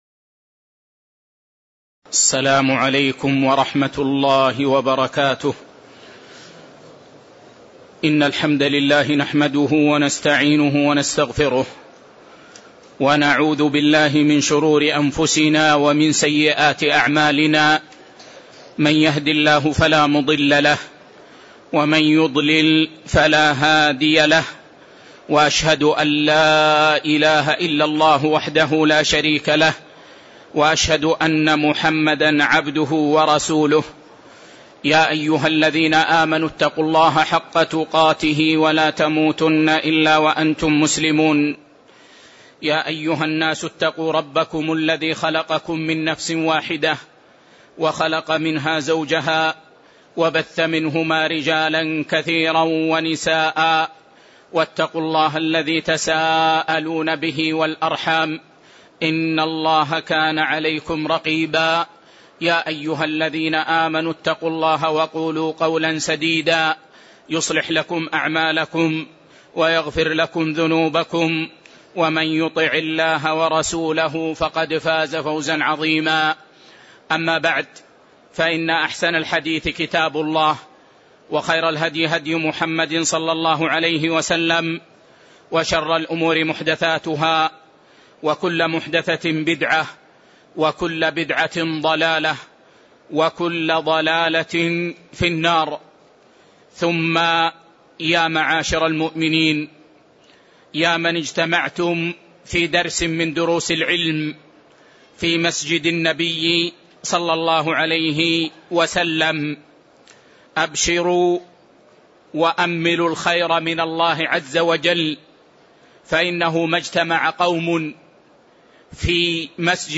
تاريخ النشر ١٣ شعبان ١٤٣٧ هـ المكان: المسجد النبوي الشيخ